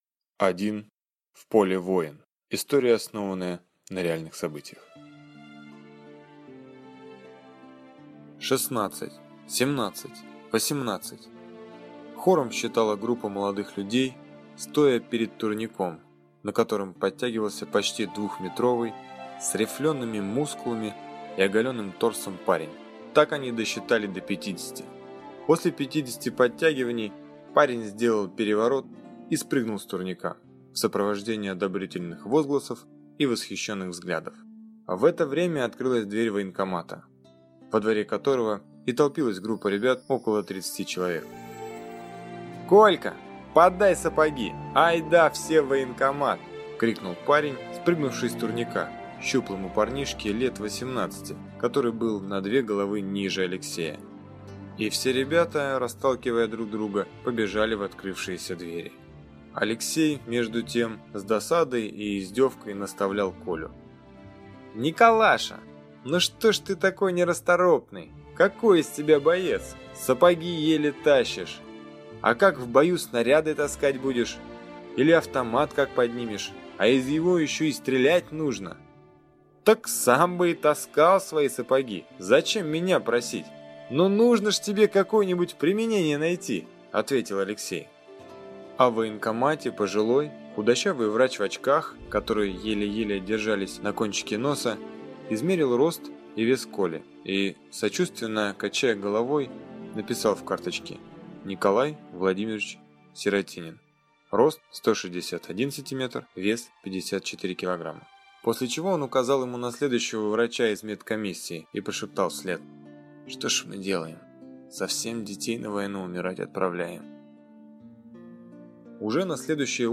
Аудиокнига И один в поле воин | Библиотека аудиокниг